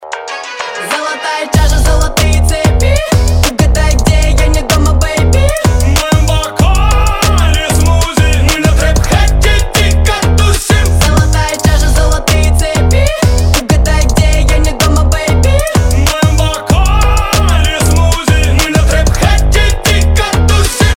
Танцевальные рингтоны
Клубные рингтоны